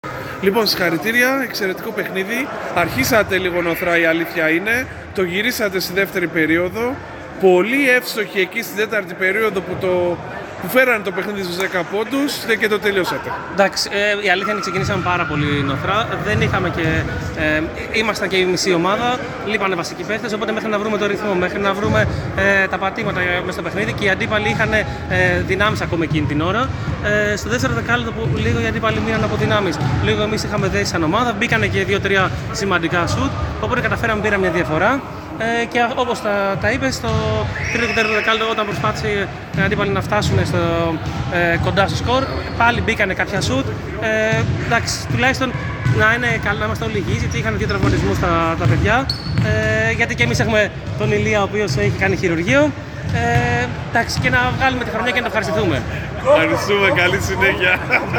GAME INTERVIEWS